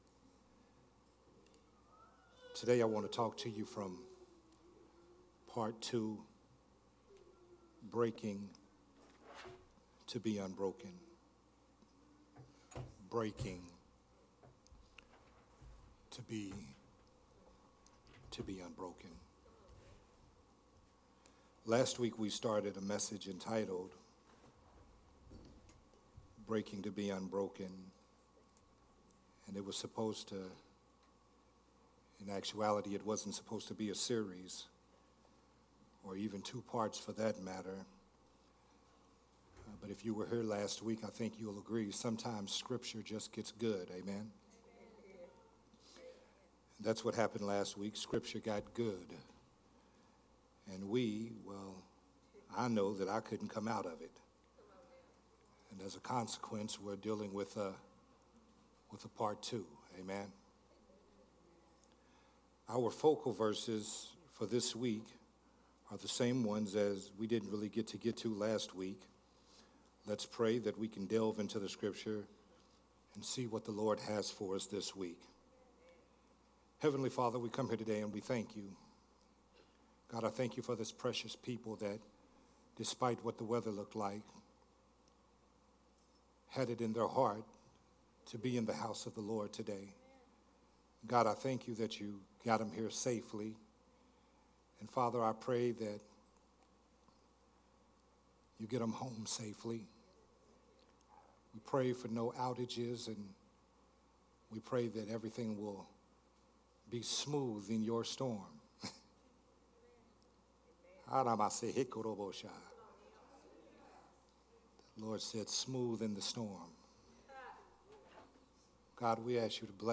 Part 2 of the sermon series
recorded at Growth Temple Ministries on January 19th